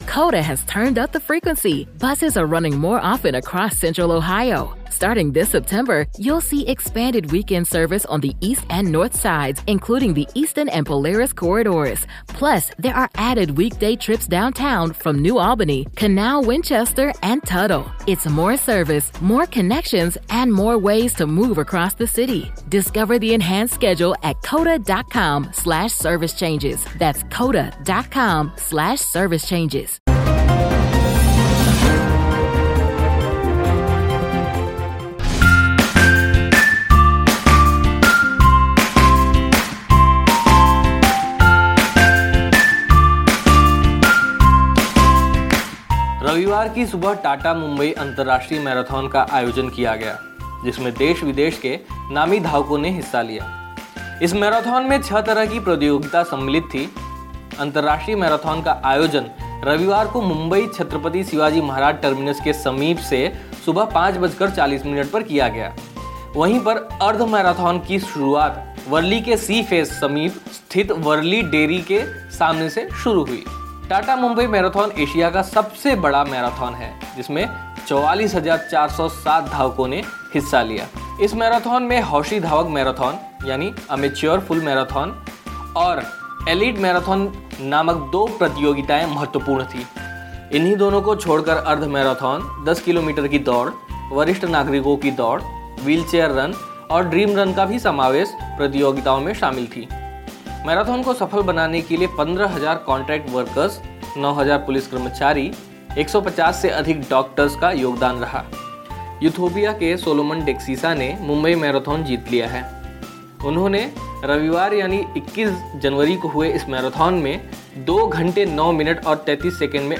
News Report / इथियोपिया के सोलोमन डेक्सिसा ने जीता मुंबई मैराथन